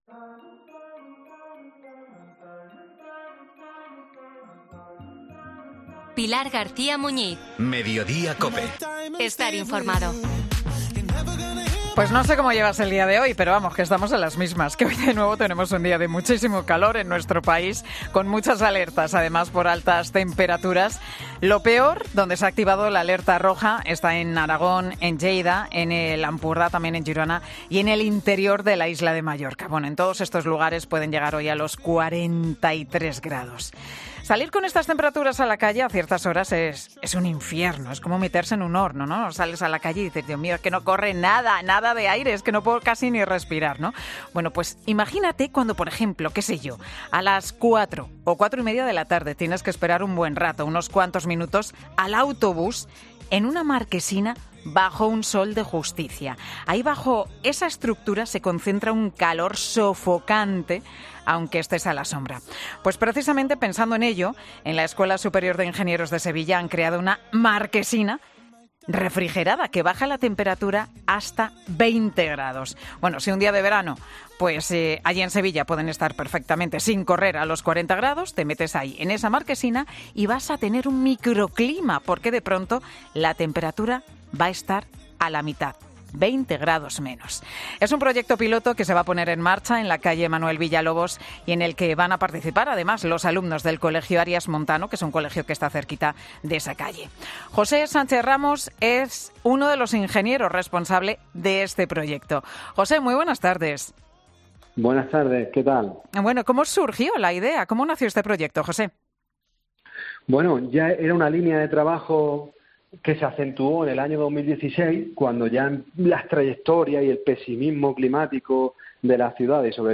Un ingeniero explica cuál es el proyecto piloto de Sevilla que luchará contra el calor en las marquesinas